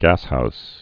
(găshous)